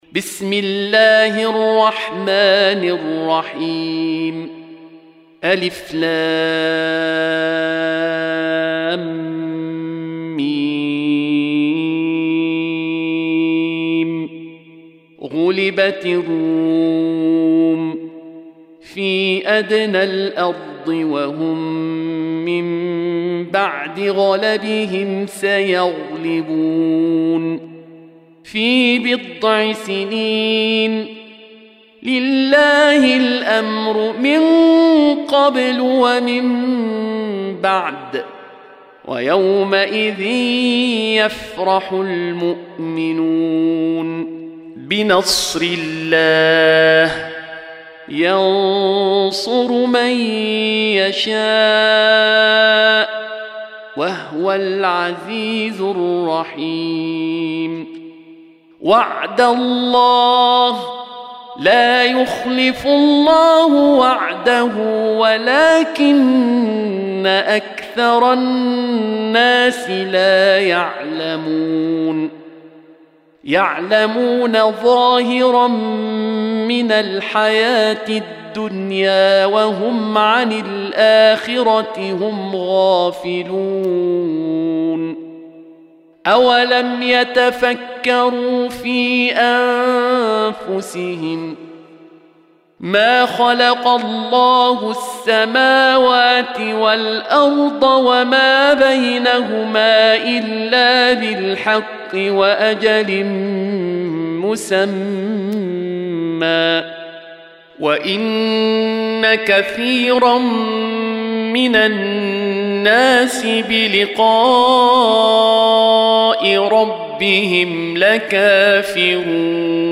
Surah Sequence تتابع السورة Download Surah حمّل السورة Reciting Murattalah Audio for 30. Surah Ar�R�m سورة الرّوم N.B *Surah Includes Al-Basmalah Reciters Sequents تتابع التلاوات Reciters Repeats تكرار التلاوات